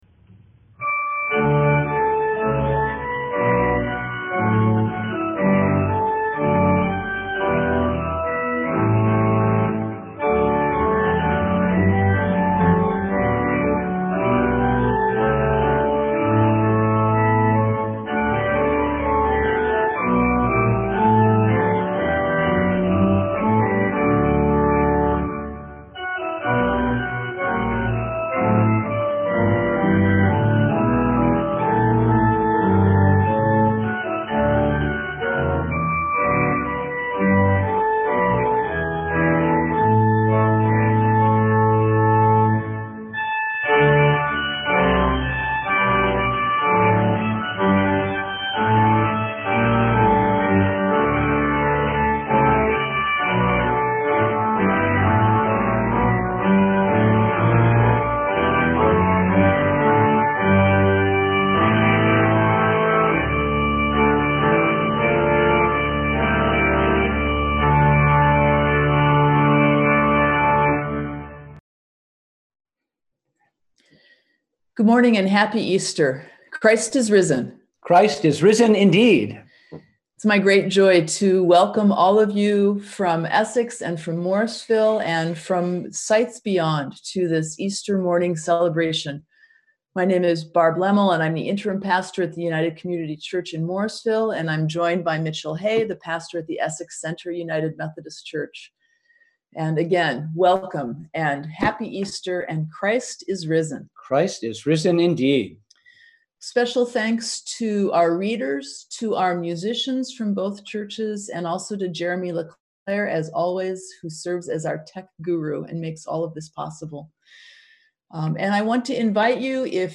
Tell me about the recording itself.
We held a virtual Easter Sunday worship on Sunday, April 12, 2020 at 10am.